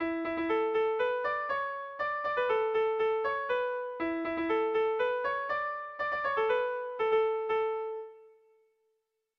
ABAB2